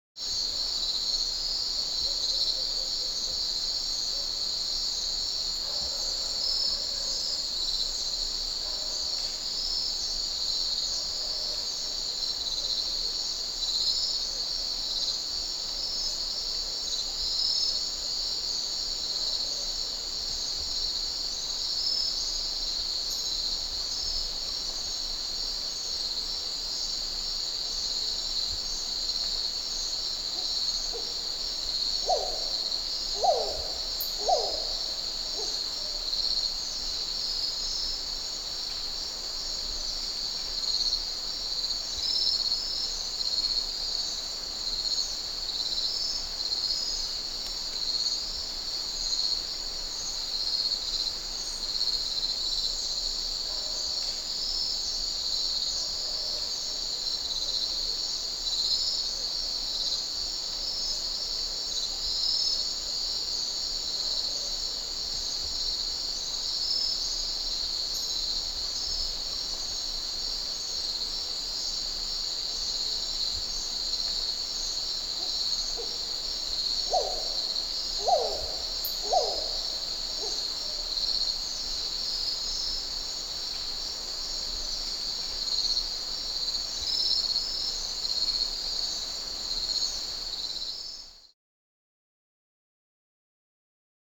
جلوه های صوتی
دانلود صدای جیرجیرک 2 از ساعد نیوز با لینک مستقیم و کیفیت بالا
برچسب: دانلود آهنگ های افکت صوتی انسان و موجودات زنده دانلود آلبوم صدای پرندگان و حشرات از افکت صوتی انسان و موجودات زنده